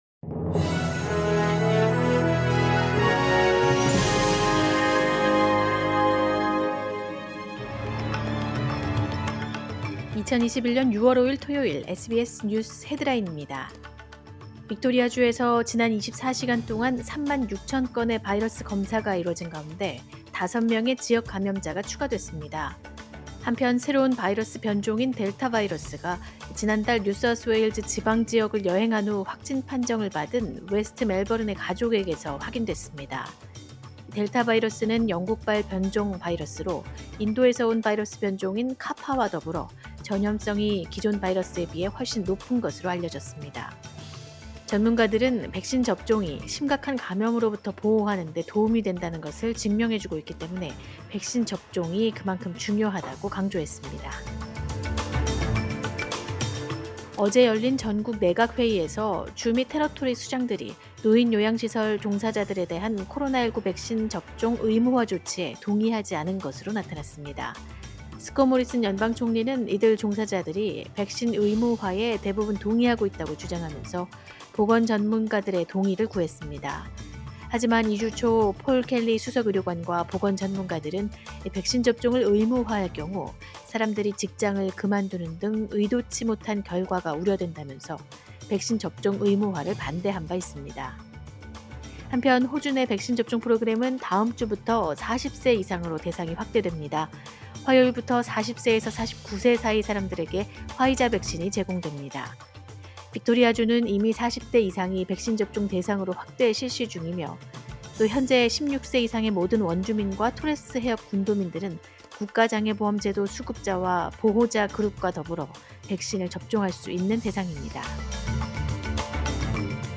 2021년 6월 5일 토요일 SBS 뉴스 헤드라인입니다.